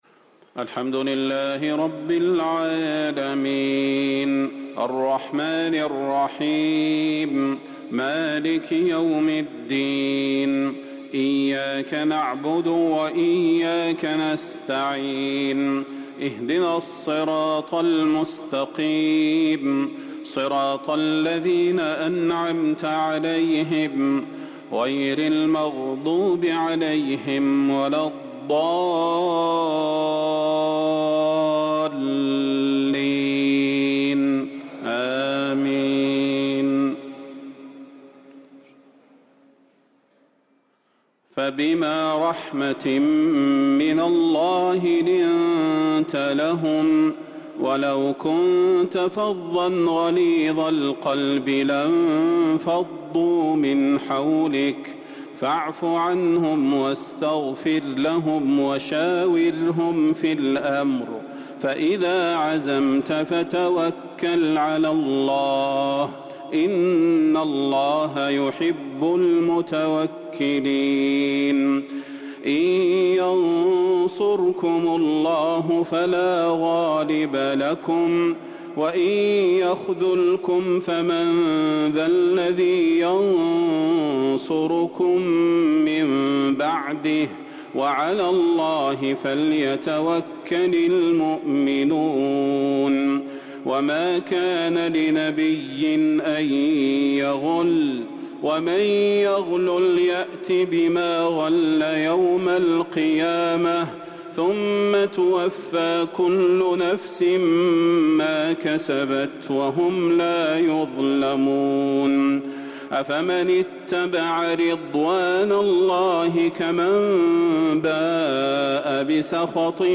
تراويح ليلة 5 رمضان 1444هـ من سورة آل عمران {159-200} Taraweeh 5st night Ramadan 1444H Surah Aal-i-Imraan > تراويح الحرم النبوي عام 1444 🕌 > التراويح - تلاوات الحرمين